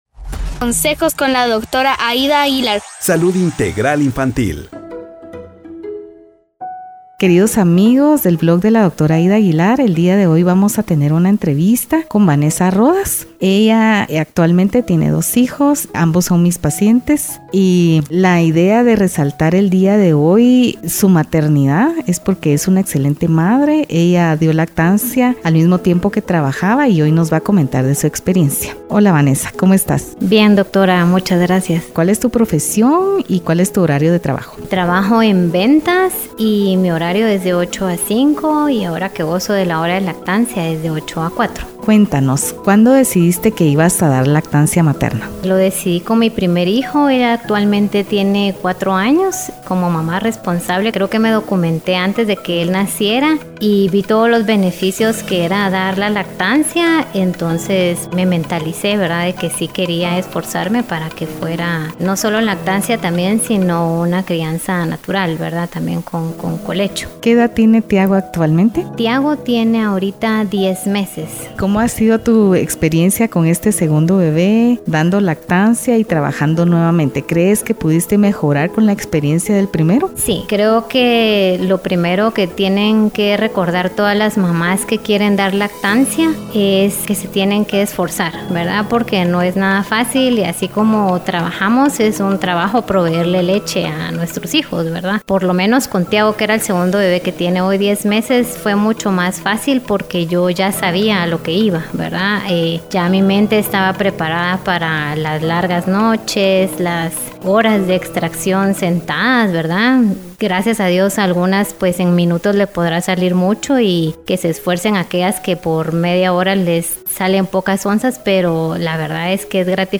Celebramos a las ¡Súper Mamás! Entrevista 2 Podcast #019